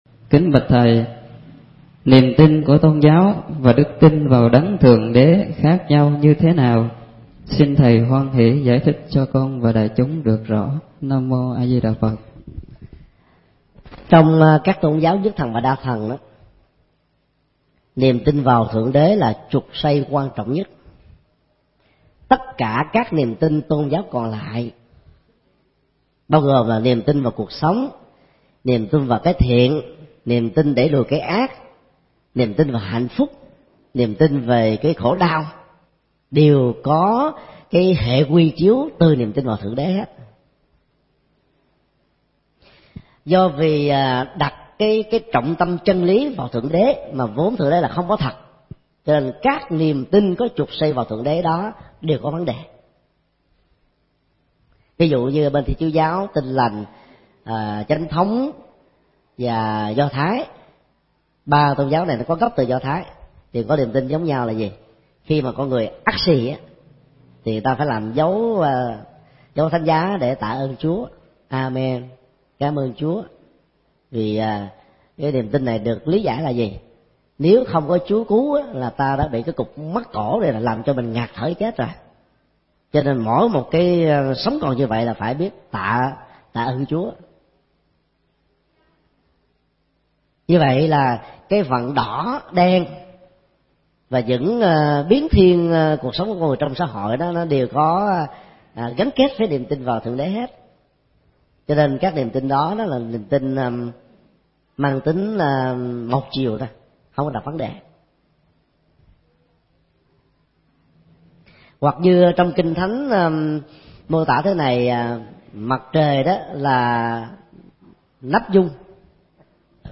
Vấn đáp: Sự khác nhau giữa niềm tin tôn giáo và đức tin vào thượng đế